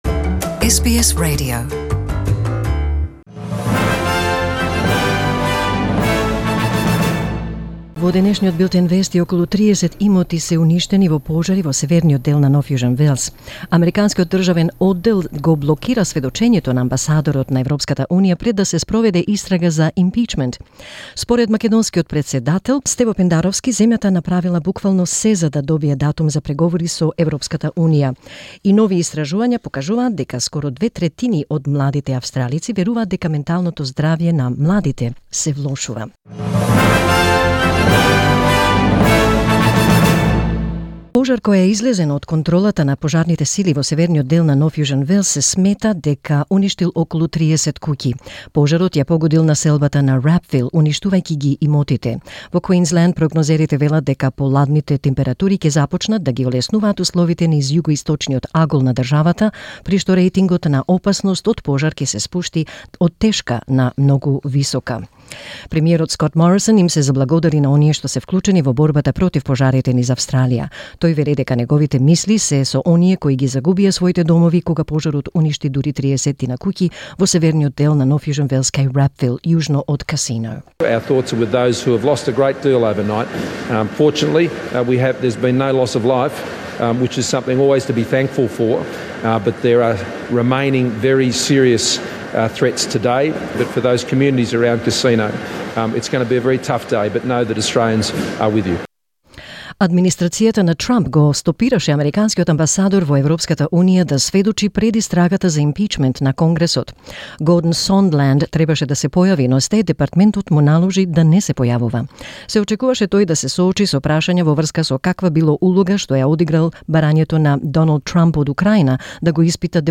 SBS News in Macedonian 9 October 2019